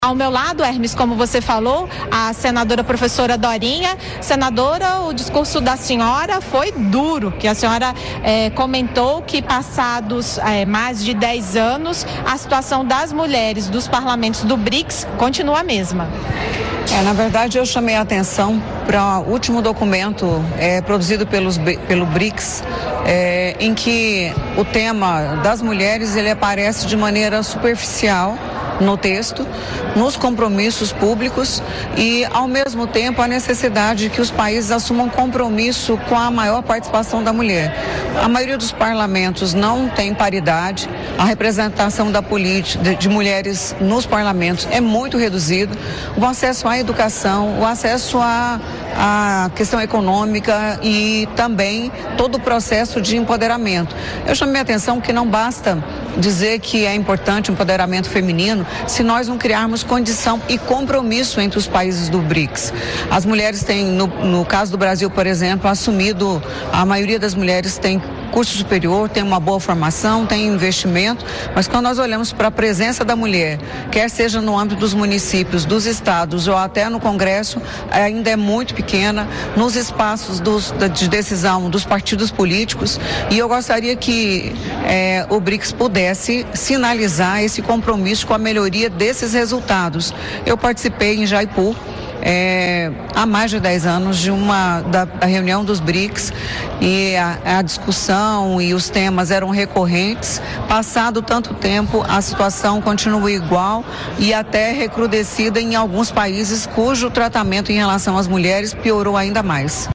A senadora Professora Dorinha Seabra (União-TO) cobrou uma ação mais contundente dos países-membros do Brics na ampliação da participação das mulheres na política. Em entrevista à Rádio Senado, nesta terça-feira (3), a senadora ressaltou que não basta esses países afirmarem que as mulheres precisam ocupar mais espaços de decisão. O necessário, segundo ela, é que os governos assumam o compromisso de mudar essa realidade por meio de ações concretas.